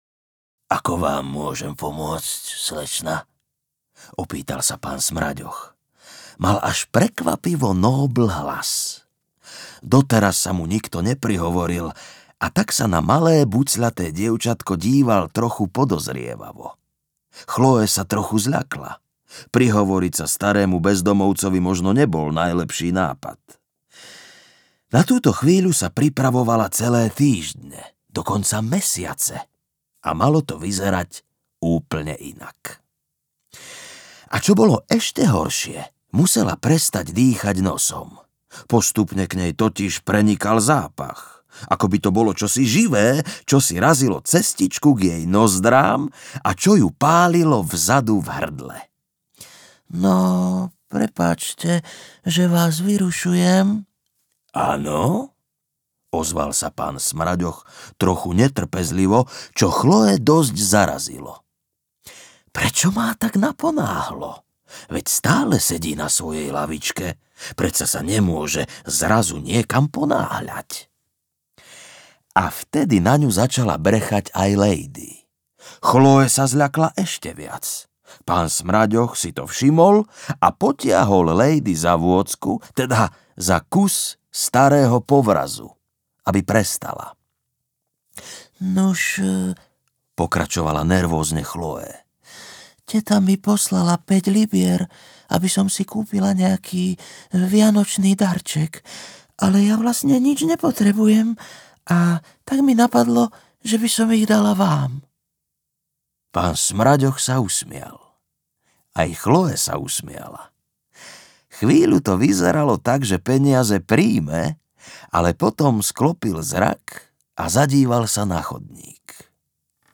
Audioknihy
Každý příběh je doprovázen zvuky přírody, příjemným praskáním ohně a je uveden hrou na bubínky.